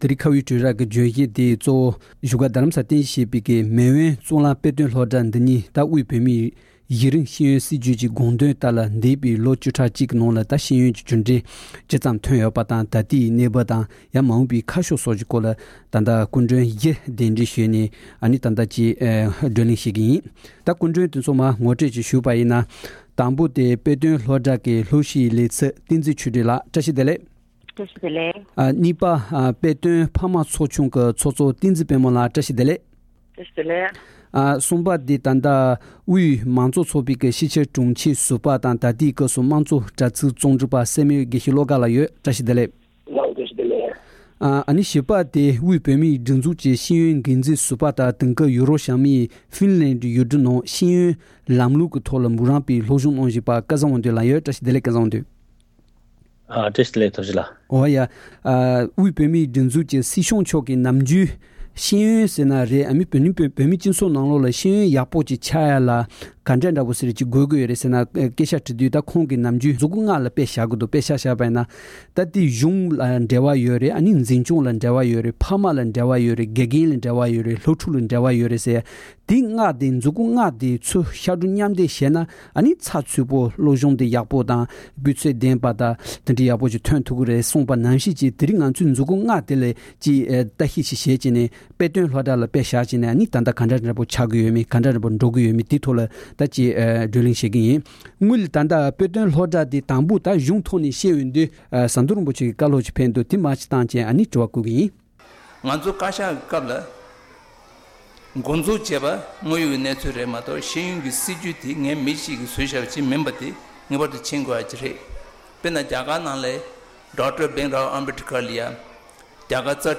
༄༅། །བཞུགས་སྒར་དྷ་རམ་ས་ལར་རྟེན་གཞི་བྱེད་པའི་མེས་དབོན་གཙུག་ལག་དཔེ་སྟོན་སློབ་གྲྭ་འདི་ཉིད་དབུས་བོད་མིའི་གཞི་རིམ་ཤེས་ཡོན་སྲིད་བྱུས་ཀྱི་དགོངས་དོན་ལྟར་འདས་པའི་ལོ་བཅུ་ཕྲག་གཅིག་ནང་གི་ཤེས་ཡོན་གྲུབ་འབྲས་ཇི་སོན་པ་དང་། ད་ལྟའི་གནས་བབ་མ་འོངས་པའི་ཁ་ཕྱོགས་སོགས་ཀྱི་སྐོར་འབྲེལ་ཡོད་ཁག་དང་བགྲོ་གླེང་ཞུས་པ་ཞིག་གསན་རོགས་གནང་།